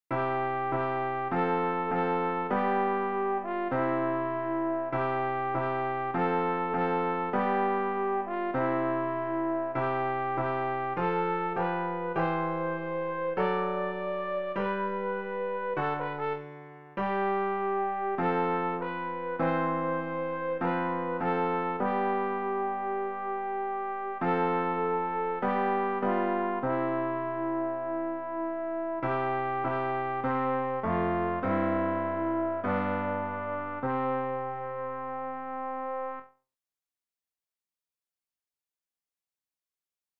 rg-860-we-shall-overcome-sopran.mp3